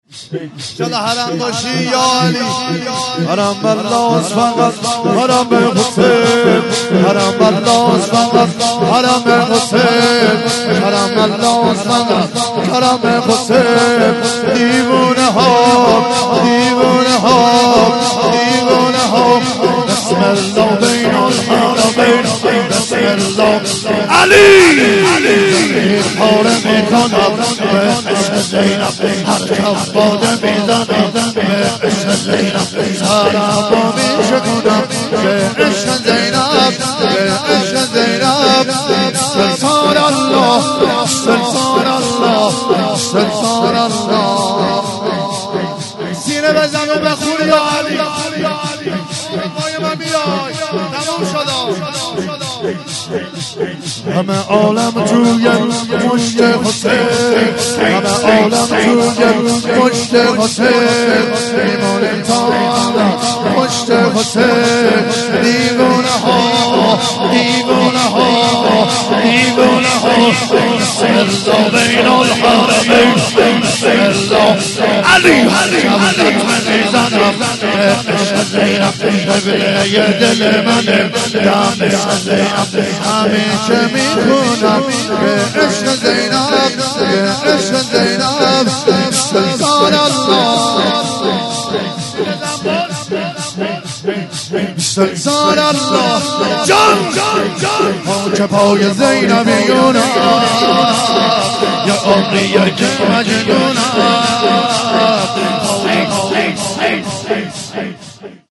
11- حرم الله ست فقط حرم حسین - شور